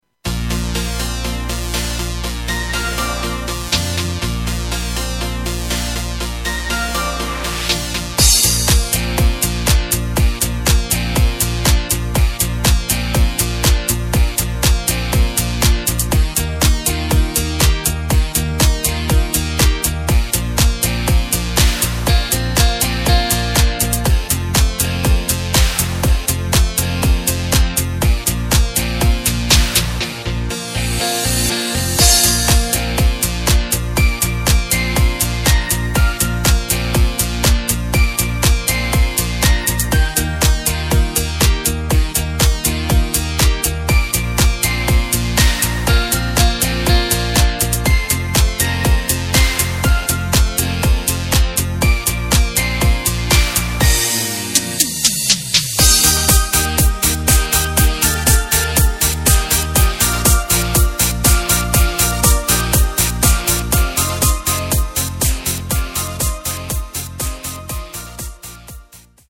Tempo:         121.00
Tonart:            Bb
Discofox aus dem Jahr 2018!
Playback mp3 Demo